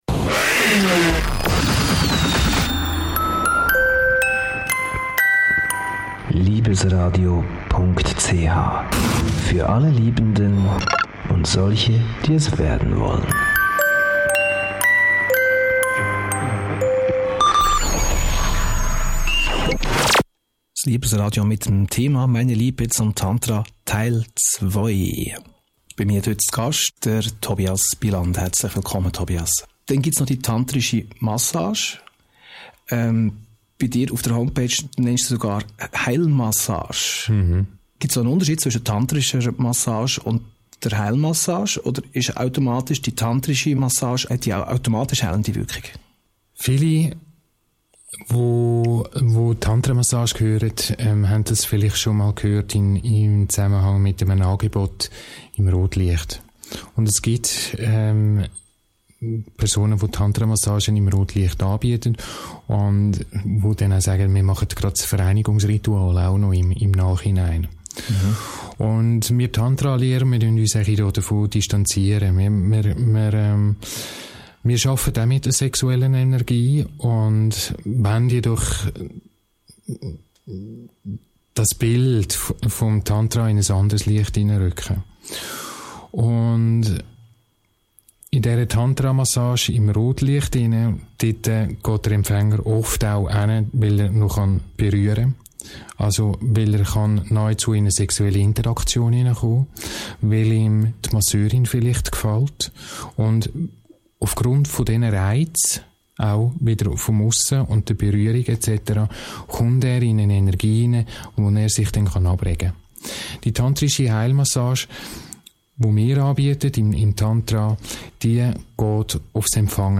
Ein Radiointerview zum Thema Tantra, im Liebesradio.